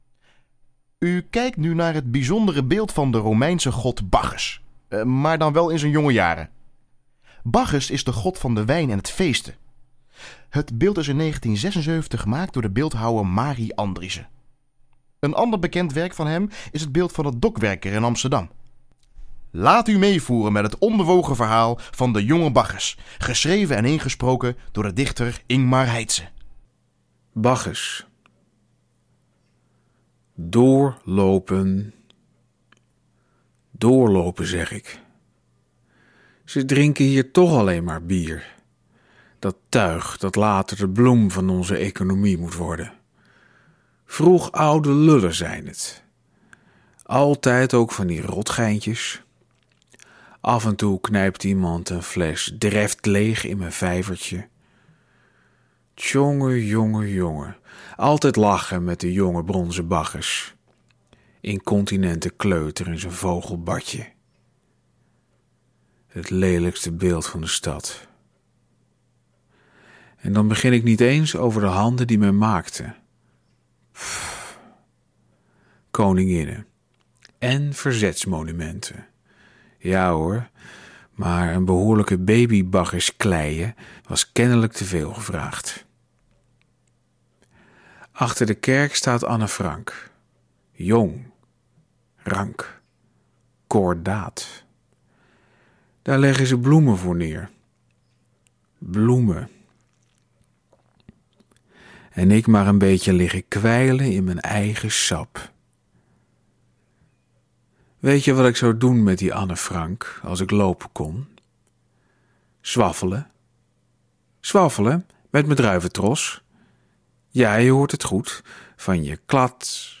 Voor de wandelroute Onbewogen Verhalen schreef en vertelt de Utrechtse dichter Ingmar Heytze een verhaal bij het beeld van de jonge Bacchus op het Janskerkhof.